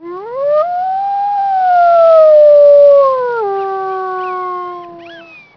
wolf2.wav